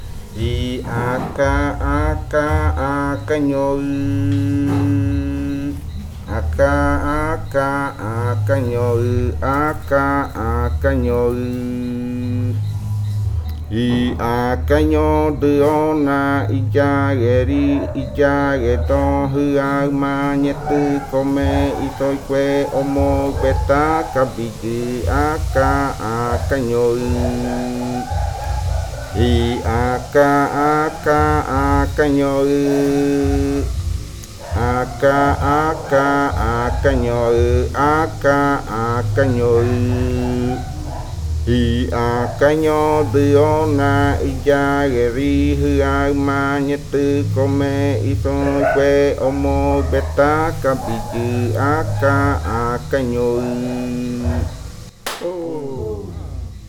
Leticia, Amazonas, (Colombia)
Grupo de danza Kaɨ Komuiya Uai
Canto fakariya de la variante Muinakɨ (cantos de la parte de abajo).
Fakariya chant of the Muinakɨ variant (Downriver chants).